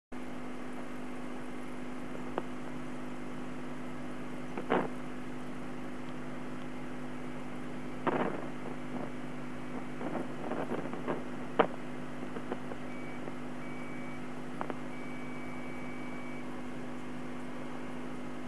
telephonelinenoice.mp3